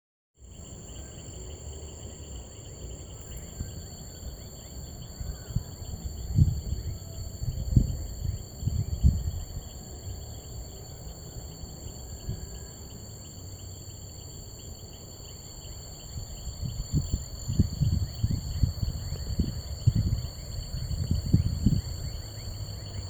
När vi går hem är det becksvart och nattens alla djur har startat sin serenad.